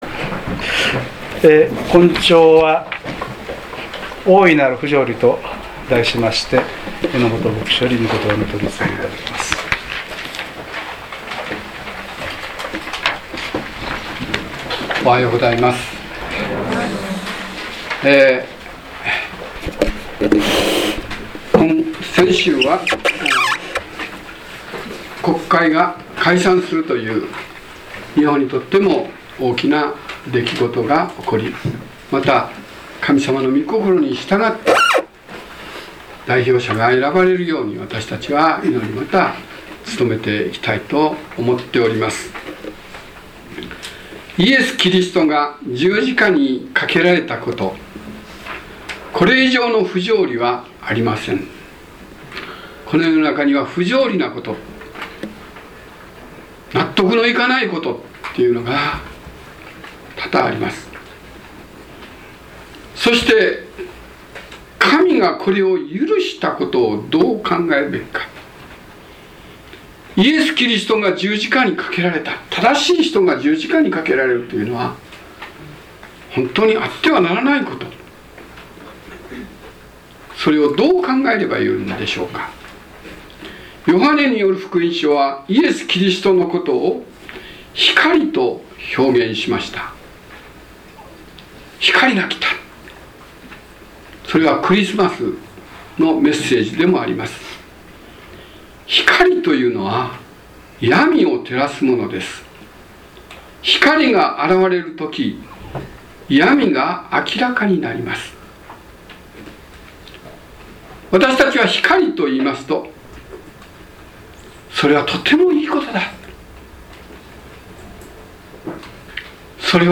説教要旨 2012年11月18日 大いなる不条理 | 日本基督教団 世光教会 京都市伏見区